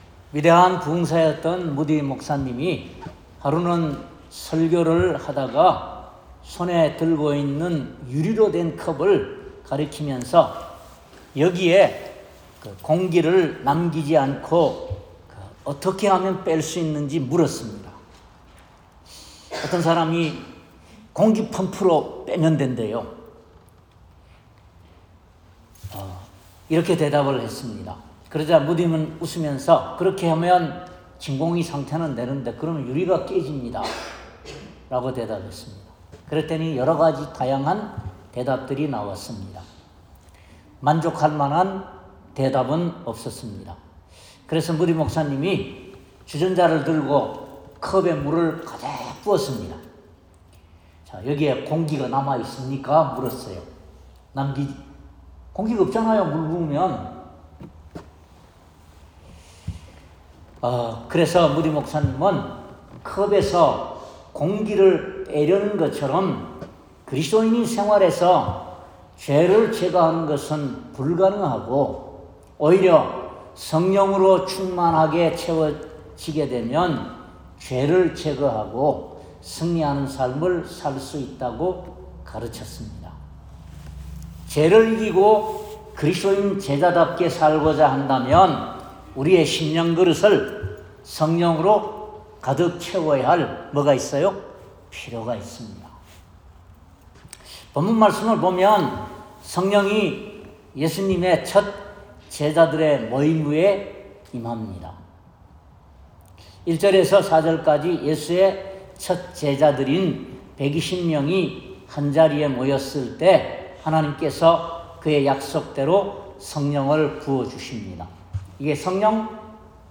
행 2:1-21절 Service Type: 주일예배 본문 1절부터 4절까지는 예수의 첫 제자들인 120문도가 한 자리에 모였을 때에 그들이 예수를 따라다녔던 그 삼 년 동안 이전에는 전혀 경험해 보지 못했던 신비한 현상들을 마가의 다락방에서 오순절날 체험하게 되었습니다.